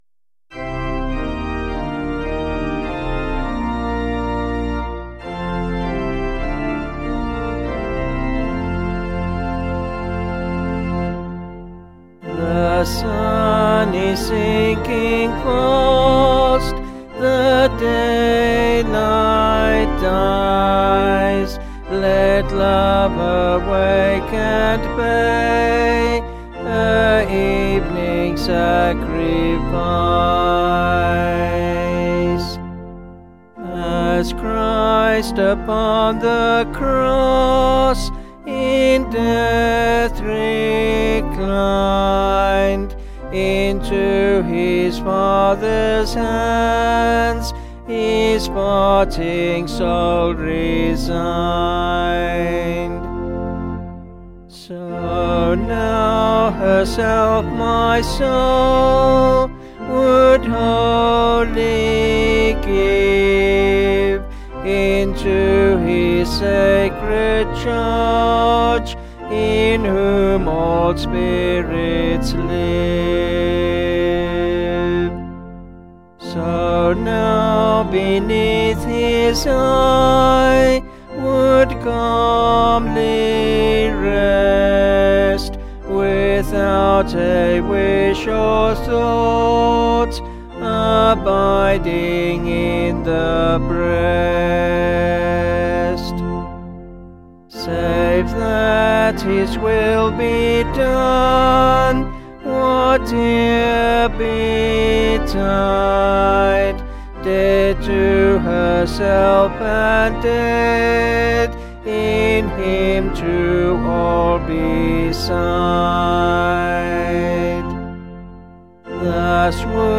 Vocals and Organ   705.9kb Sung Lyrics